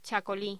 Locución: Txacolí
voz